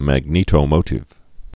(măg-nētō-mōtĭv)